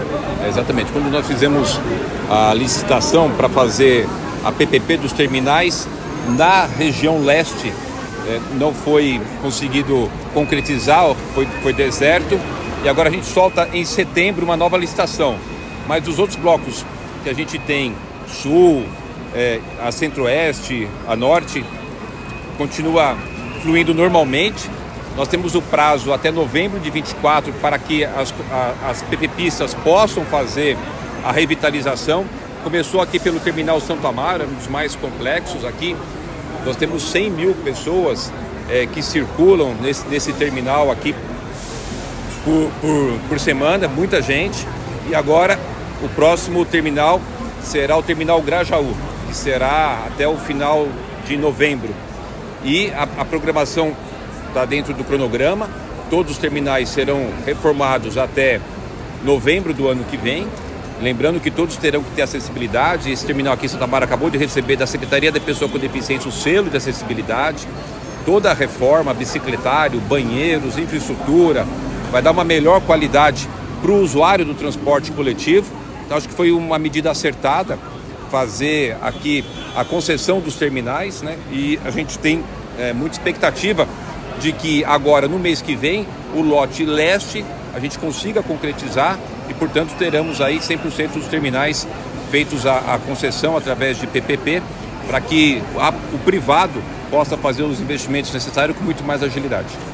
A previsão é do prefeito Ricardo Nunes, que esteve na manhã desta segunda-feira, 28 de agosto de 2023, na entrega das obras de revitalização do terminal Santo Amaro, na zona Sul de São Paulo.